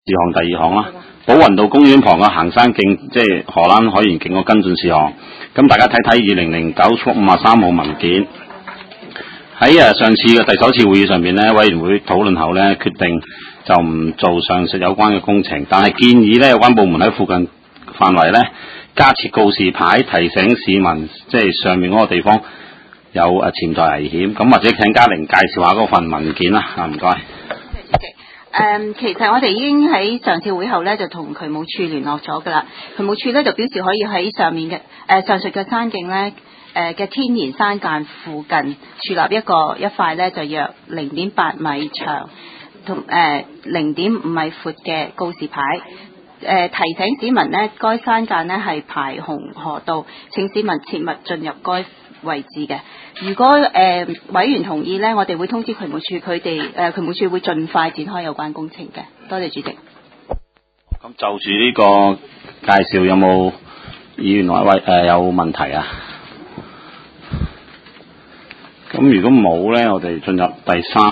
地區工程及設施管理委員會第十二次會議
灣仔民政事務處區議會會議室